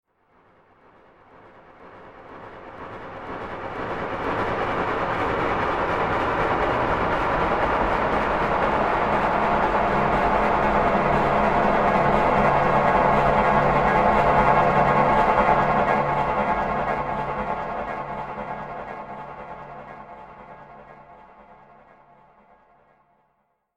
دانلود آهنگ قطار 8 از افکت صوتی حمل و نقل
جلوه های صوتی
دانلود صدای قطار 8 از ساعد نیوز با لینک مستقیم و کیفیت بالا